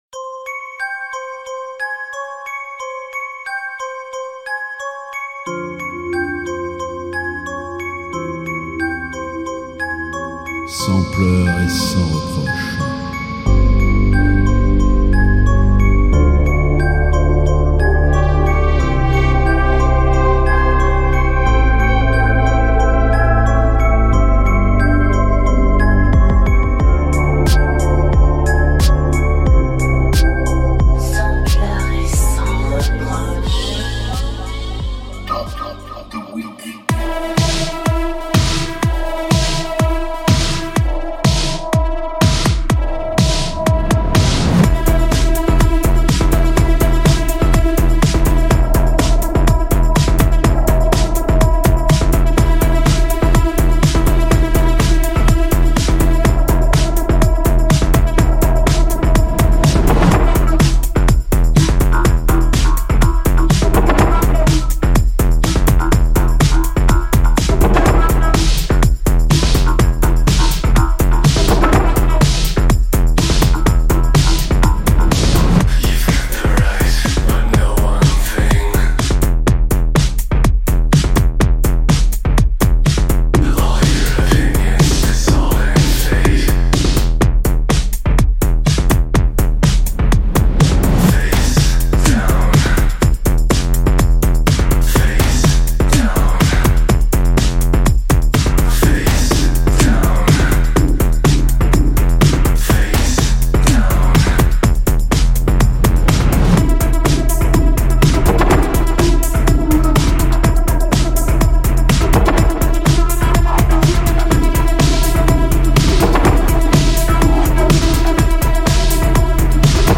Radio broadcast
EBM - DARKTECHNO - INDUSTRIEL & RELATED MUSIC